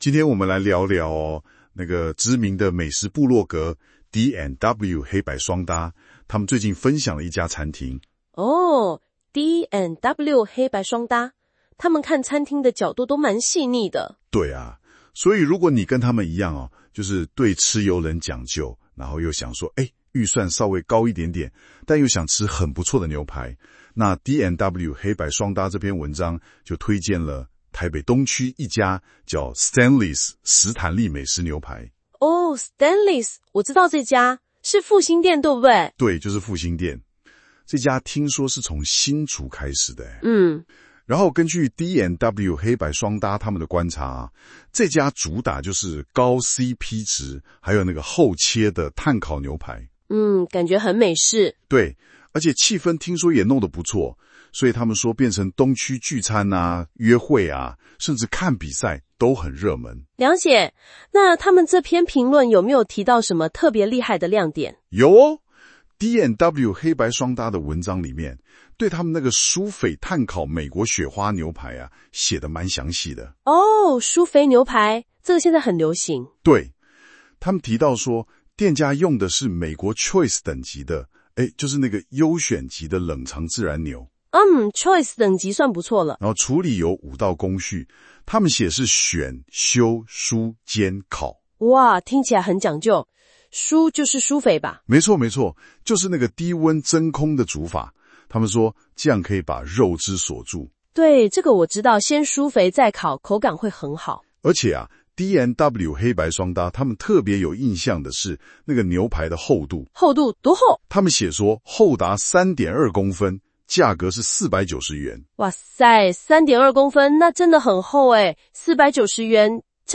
新功能!現在用【說】的方式介紹文章哦!